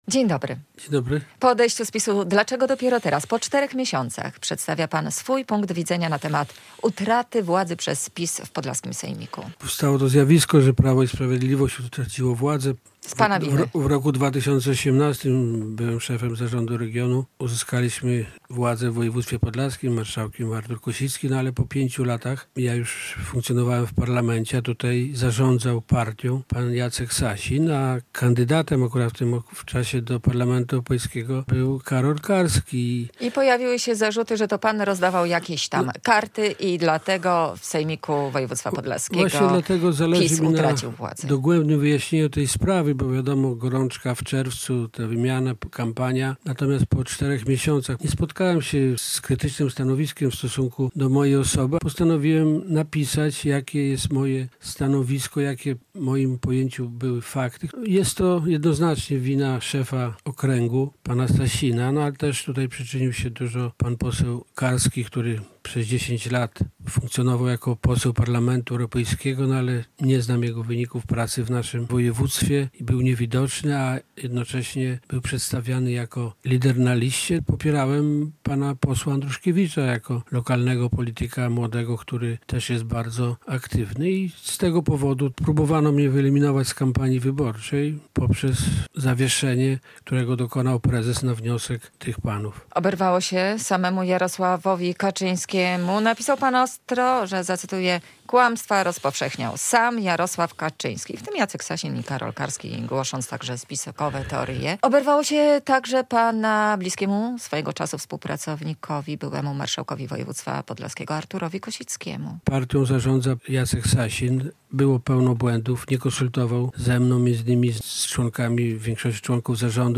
Radio Białystok | Gość | Krzysztof Jurgiel [wideo] - polityk, samorządowiec